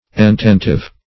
Ententive \En*ten"tive\, a.